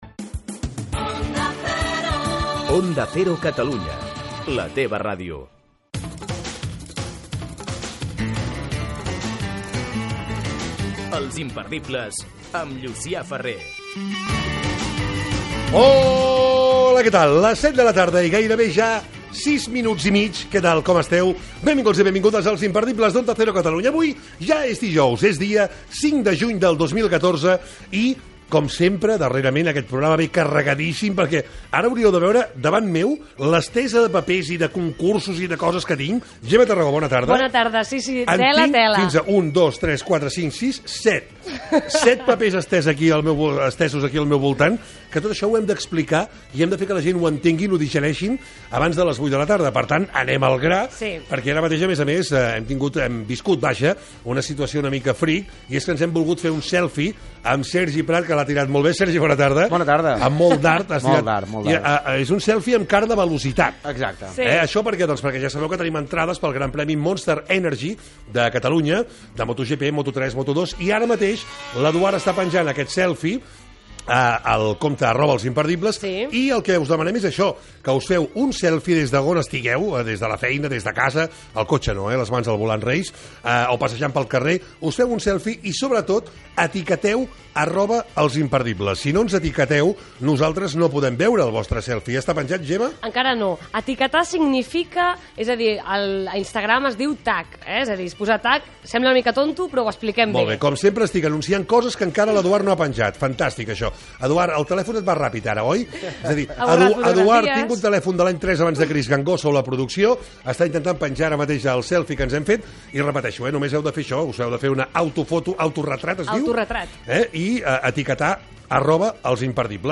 Indicatius de l'emissora i del programa, hora, benvinguda, sumari de continguts, equip, concursos, artistes que actuaran a l'Andorra Red Music, temes d'actualitat curiosos Gènere radiofònic Entreteniment